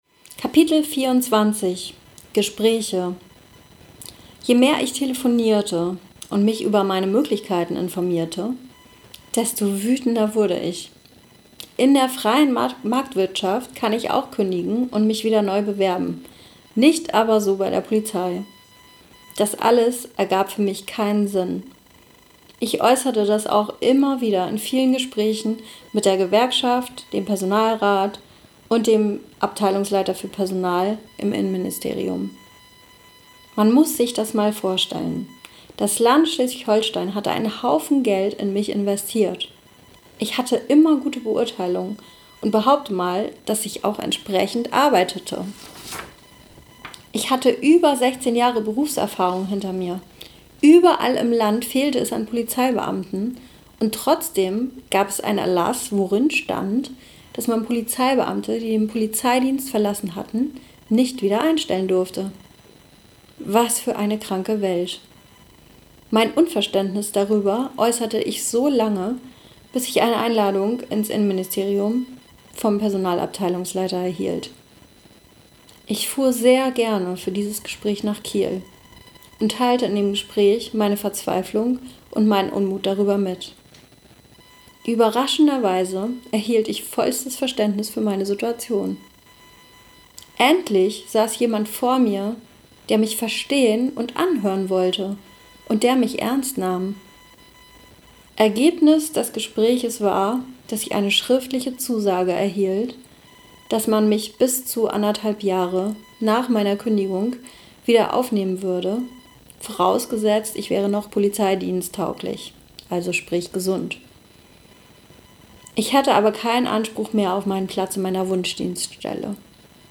In der Audiografie erzähle ich Dir meine Lebensgeschichte.